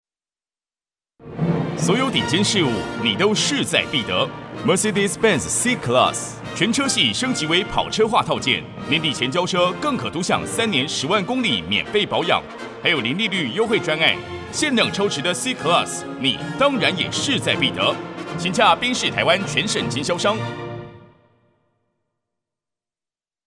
國語配音 男性配音員
穩重-BENZ
1. 渾厚穩重的聲線
穩重-BENZ.mp3